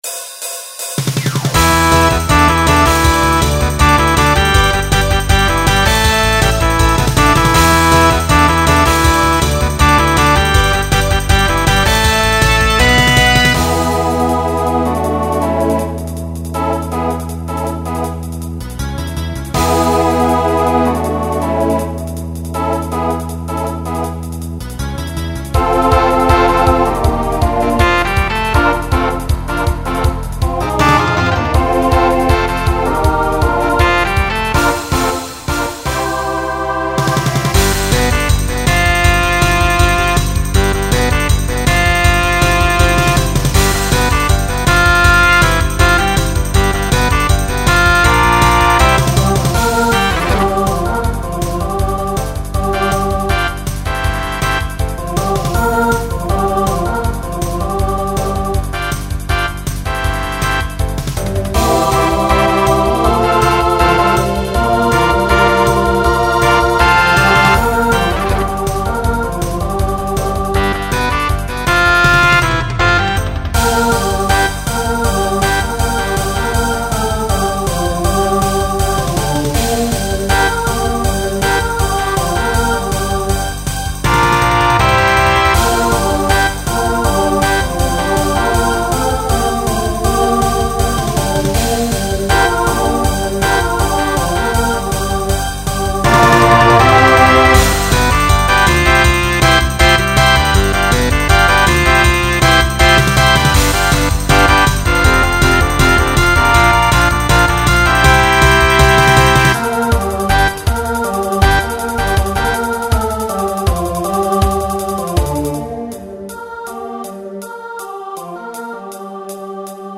Rock Instrumental combo
Voicing SATB